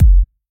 heartbeat.ogg